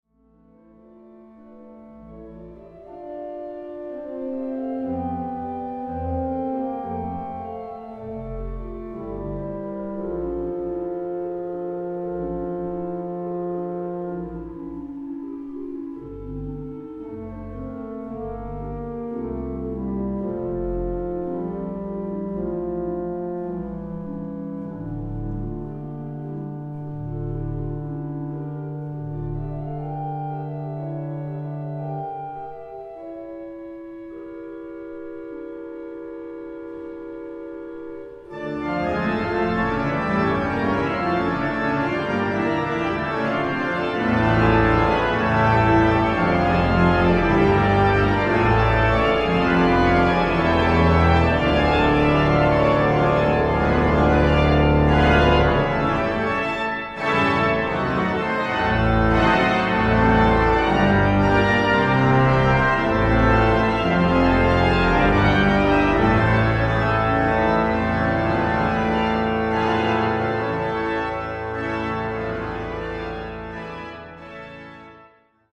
Konzert CD
WALCKER-Orgel von 1928.